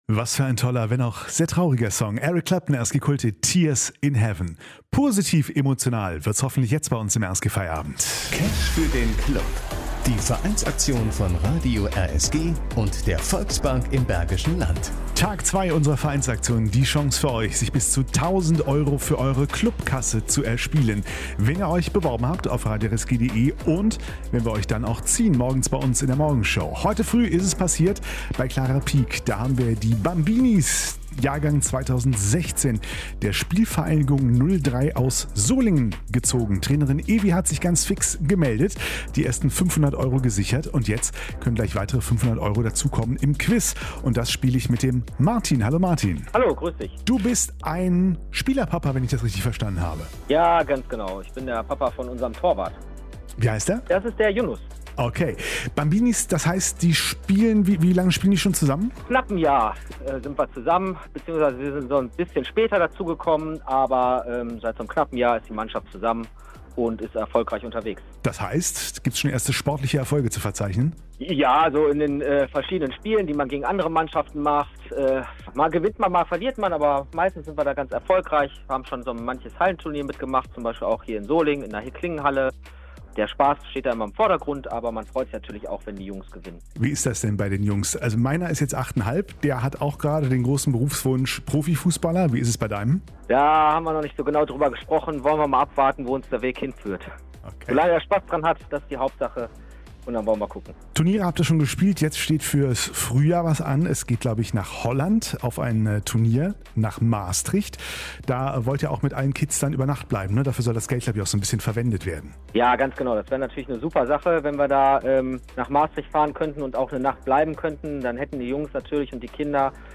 Cash für den Club 2023Spvg. Solingen 03 G1 - Interview
cash_interview_solingen_03.mp3